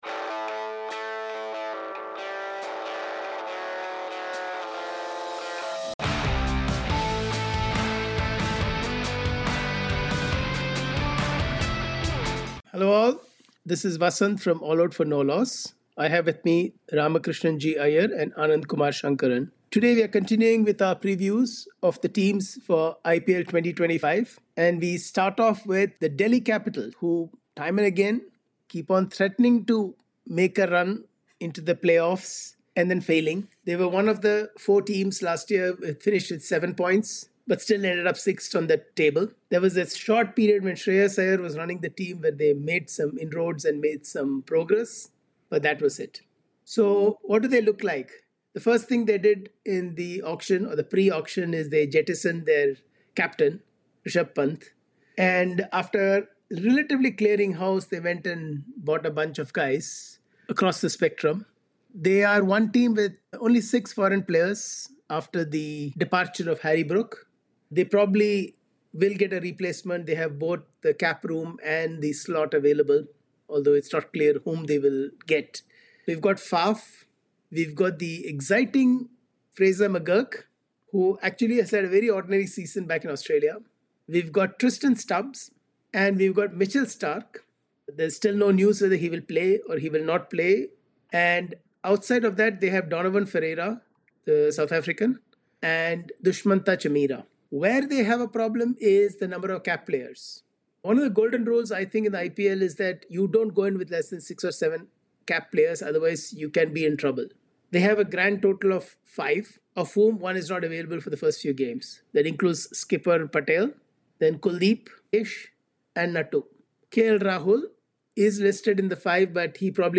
In this conversation we will take a look at the Delhi Capitals, Rajasthan Royals, Sunrisers Hyderabad and Mumbai Indians.